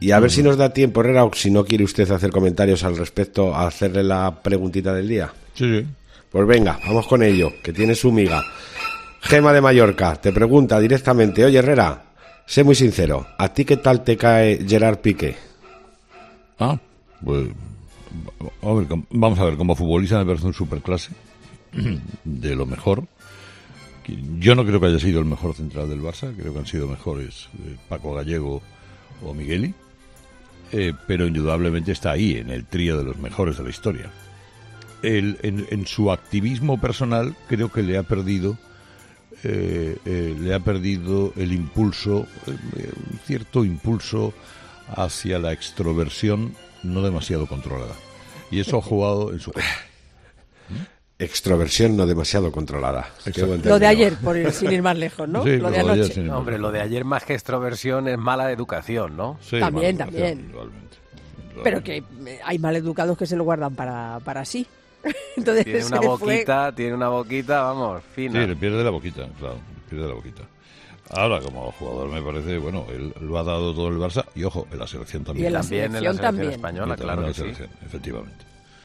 Escucha a Carlos Herrera valorar la actuación de Piqué:
Escucha a Carlos Herrera comentar la expulsión de Piqué